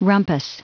Prononciation du mot rumpus en anglais (fichier audio)
Prononciation du mot : rumpus